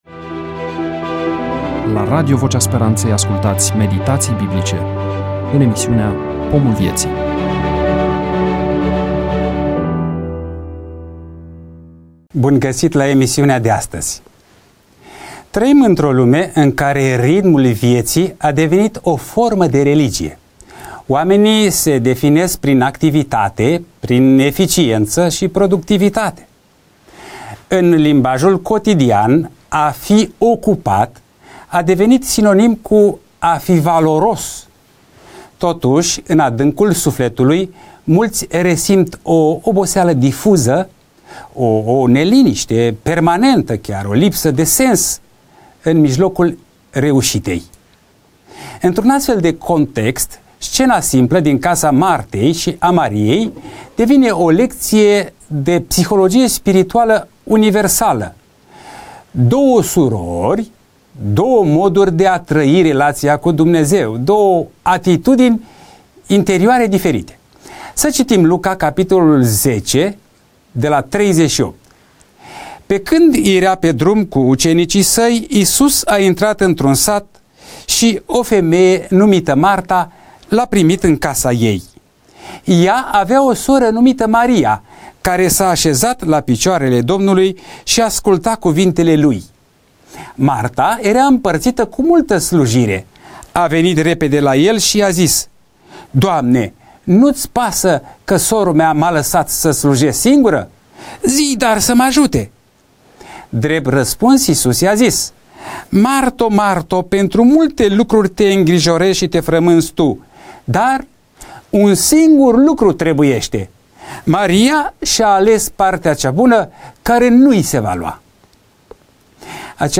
EMISIUNEA: Predică DATA INREGISTRARII: 26.12.2025 VIZUALIZARI: 10